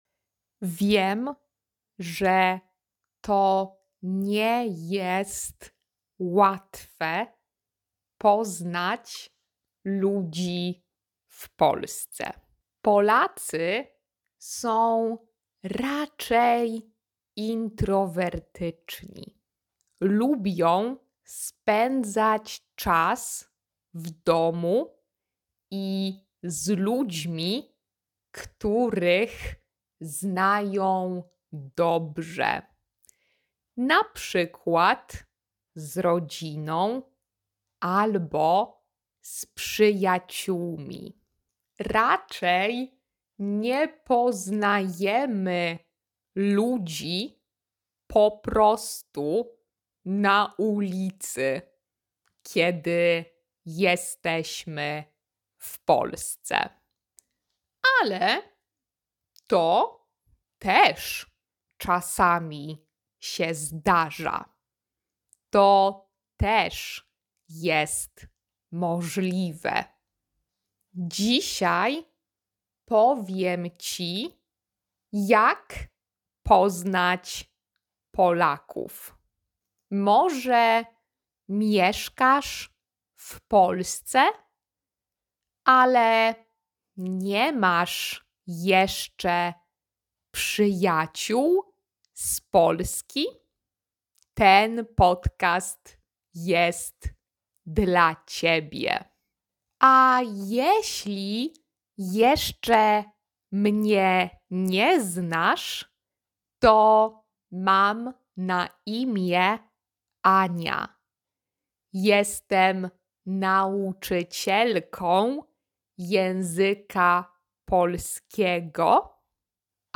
#70 Slow Polish Podcast: Jak mieć przyjaciół w Polsce?
Slow-Polish-podcast.mp3